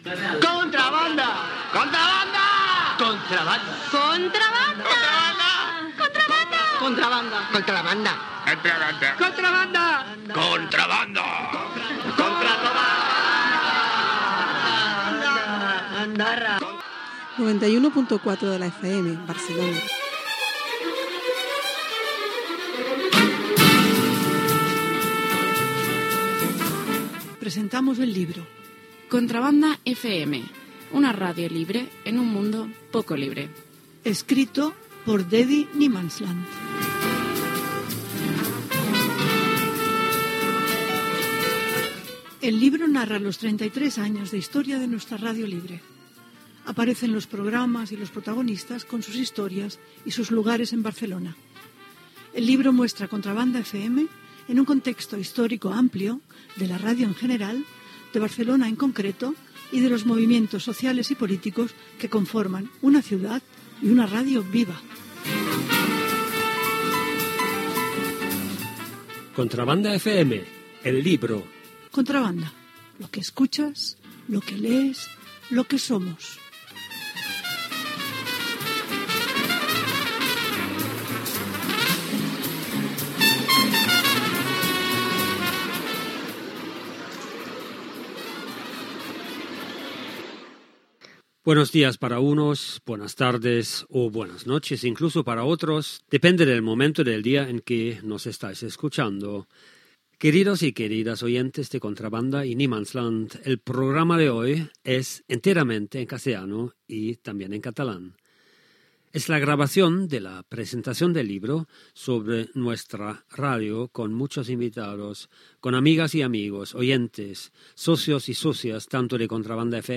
Indicatiu de l'emissora
Programa dedicat a l'acte de presentació del llibre a l'Ateneu Enciclopèdic